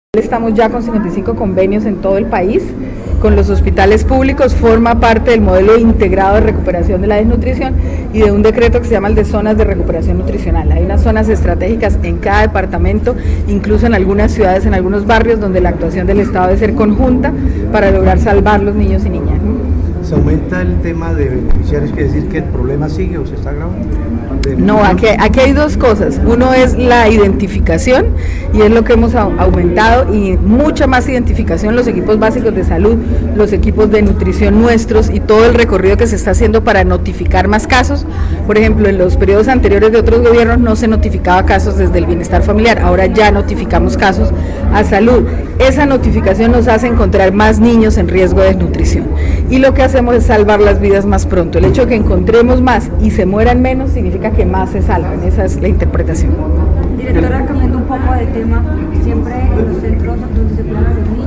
Directora del Icbf, Astrid Cáceres Cárdenas